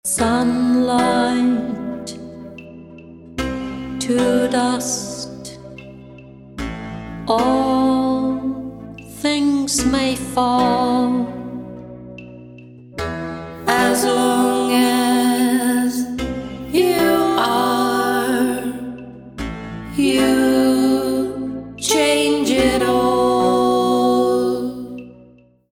sound & editing
writing & voice